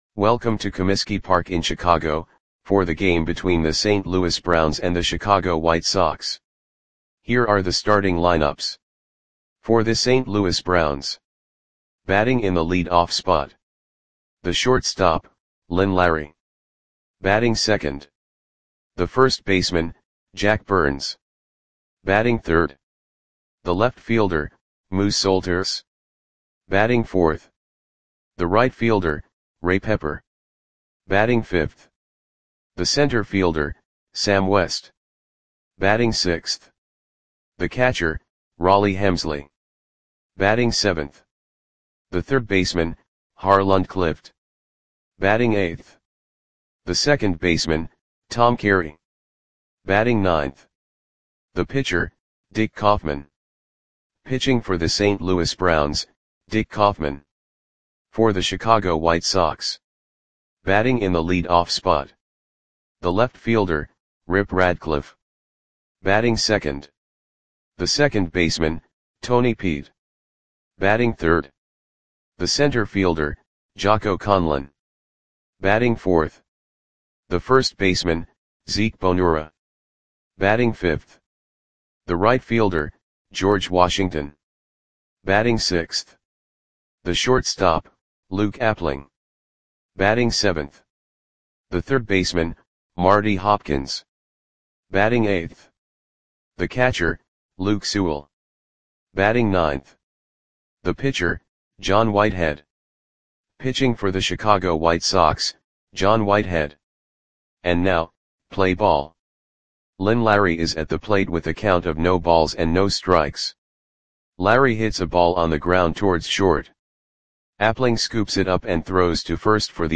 Audio Play-by-Play for Chicago White Sox on August 3, 1935
Click the button below to listen to the audio play-by-play.